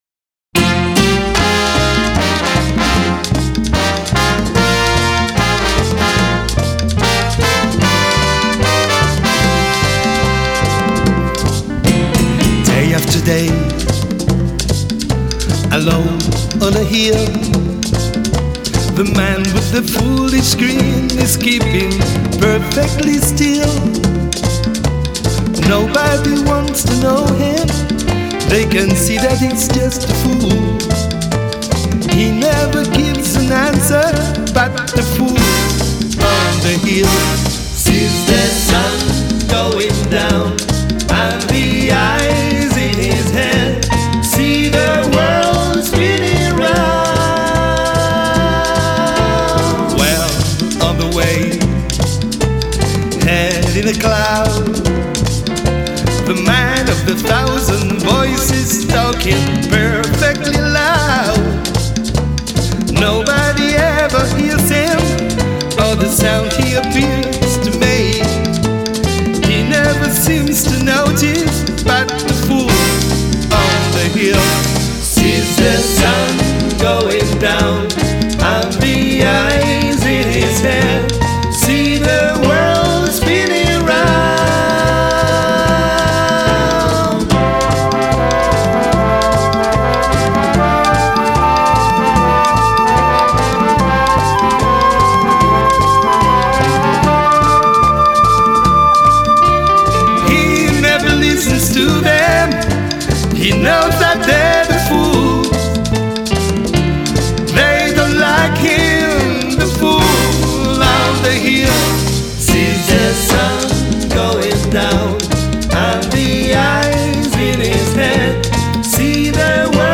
Ищу диско-версии данной песни с вокалом :)